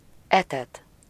Ääntäminen
Synonyymit substanter sustenter Ääntäminen France: IPA: [nu.ʁiʁ] Haettu sana löytyi näillä lähdekielillä: ranska Käännös Ääninäyte 1. etet 2. táplál Määritelmät Verbit Sustenter , servir d’ aliment .